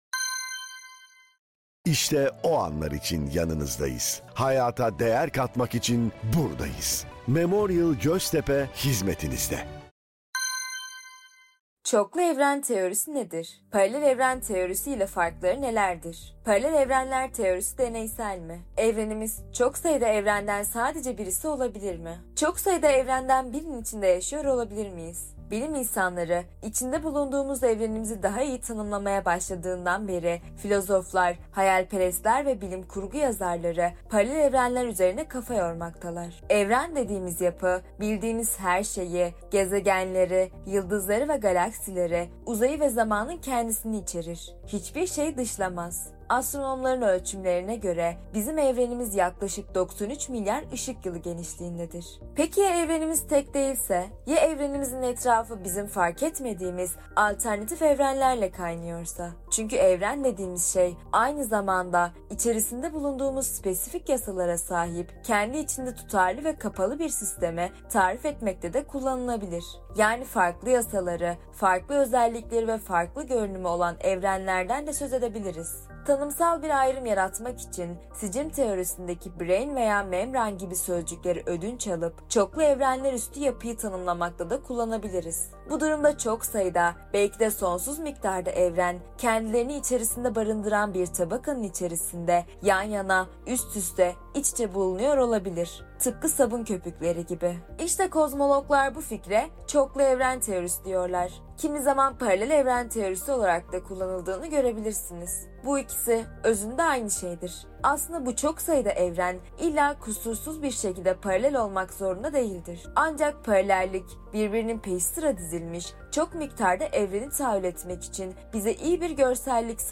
Bu yayın listemizde, Evrim Ağacı'nda yayınladığımız içeriklerden seçilmiş yazılarımızı yazarlarımızın kendileri, diğer yazarlarımız veya ses sanatçıları seslendirerek, sizlerin kulaklarına ulaştırıyor.